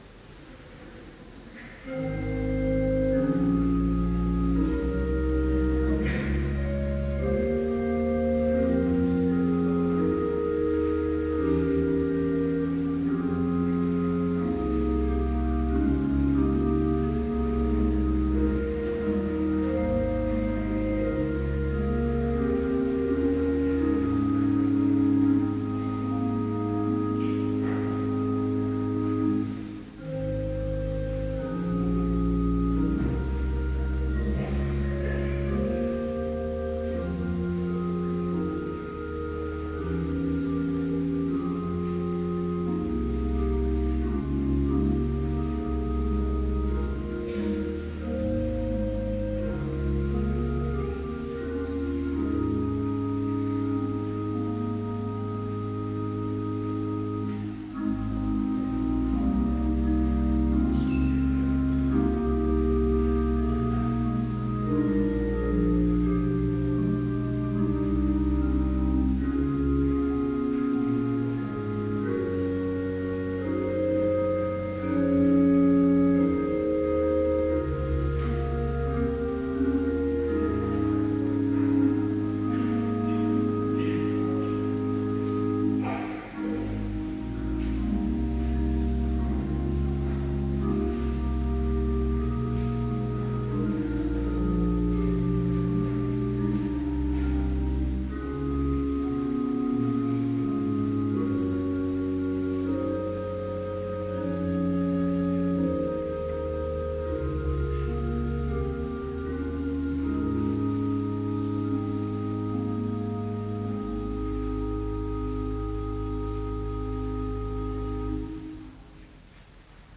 Complete service audio for Evening Vespers - September 11, 2013